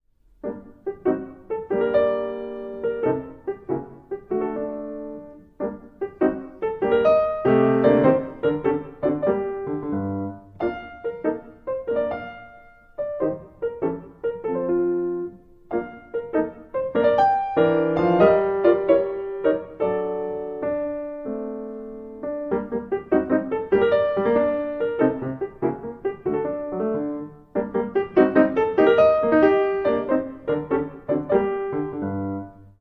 III Allegro moderato, mm.1-24